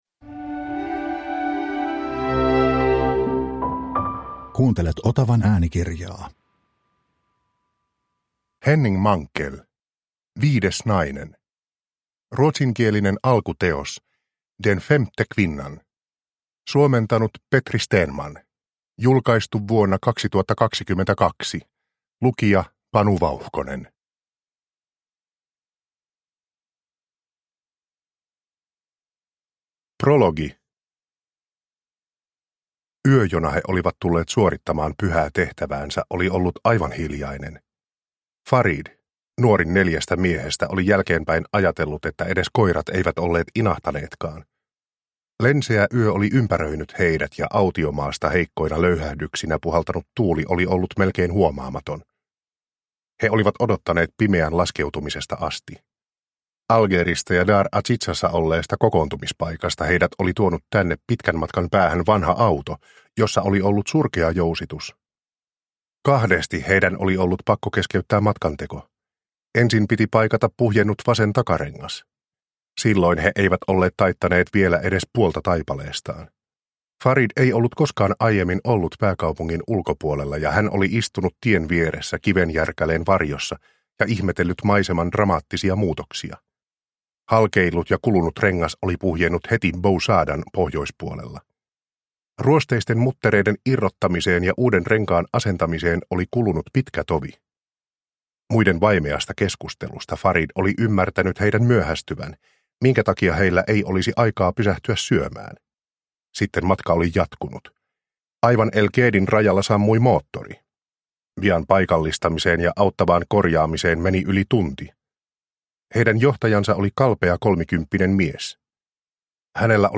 Viides nainen – Ljudbok – Laddas ner